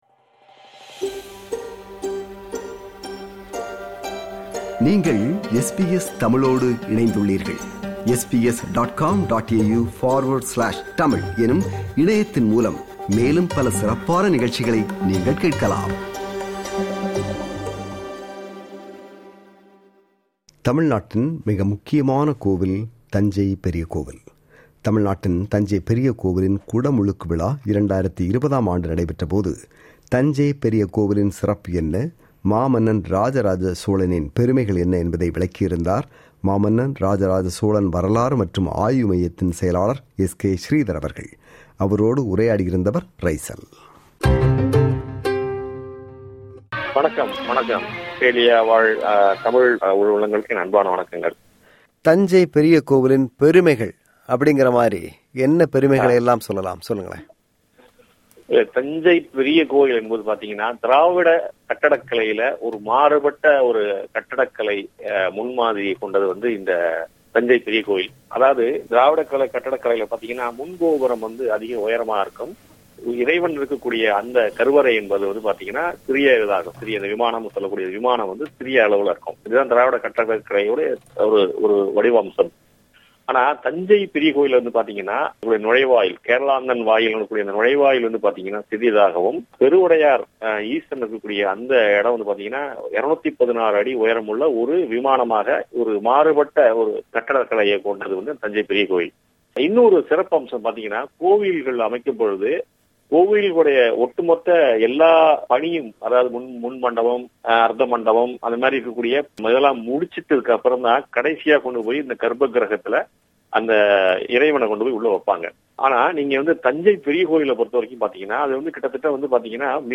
அவரோடு உரையாடியவர்